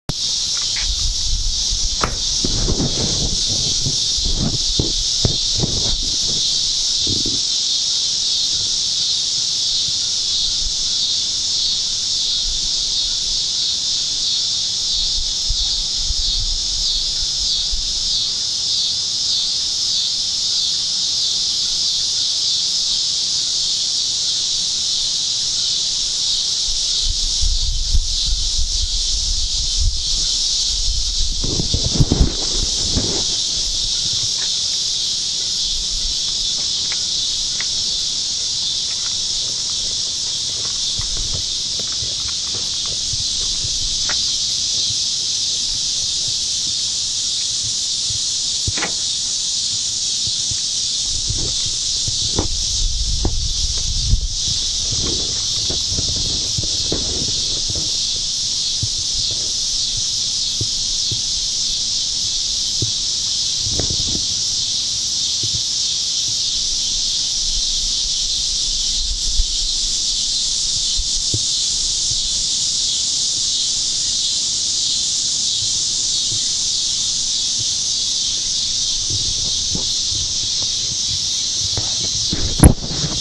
them, I've attached a small WMA stereo file of them.
Name: Cicadas July 17.wma